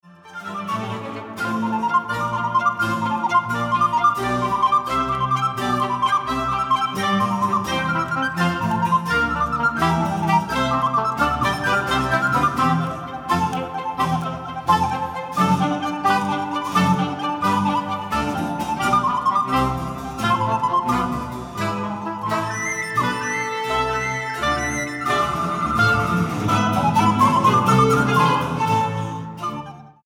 Concierto para flauta de pico y continuo